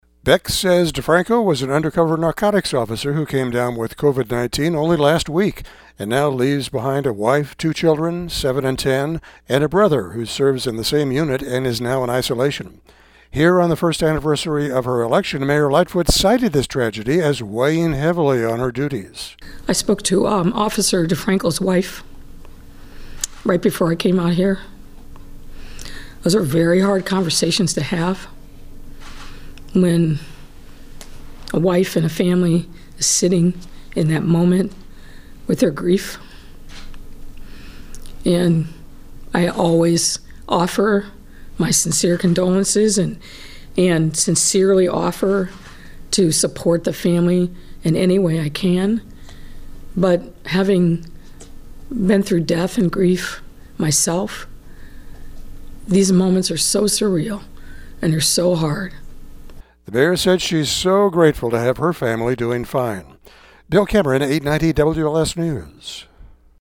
Here on the first anniversary of her election, Mayor Lightfoot spoke emotionally about how this tragedy weighs heavily on her duties.